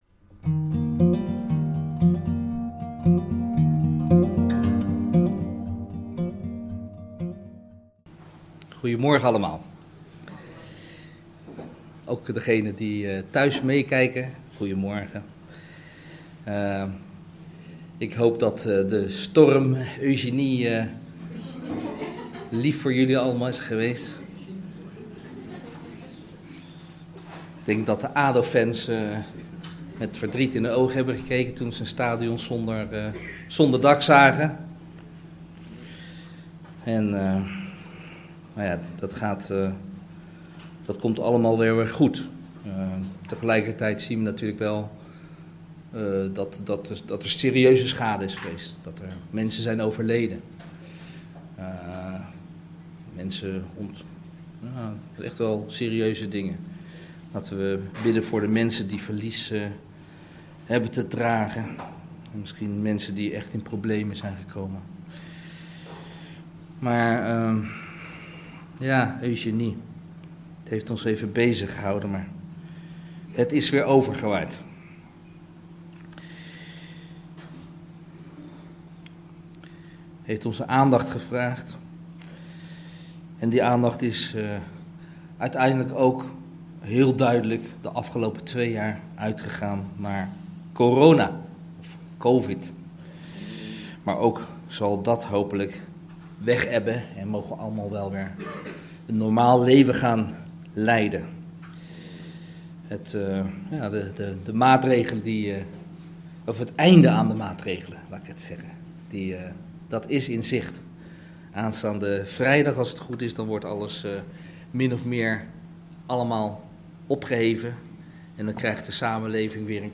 Preken